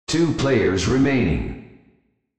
Announcer
TwoPlayersRemaining.wav